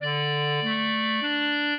clarinet
minuet15-5.wav